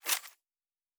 pgs/Assets/Audio/Sci-Fi Sounds/Weapons/Weapon 13 Foley 1.wav at 7452e70b8c5ad2f7daae623e1a952eb18c9caab4
Weapon 13 Foley 1.wav